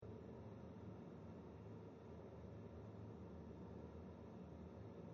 signaal ontvangzijde zonder voorzetwand, berekend met de prognosemethode
De prognosemethode geeft in dit geval een 6 dB te sterke verzwakking van het signaal!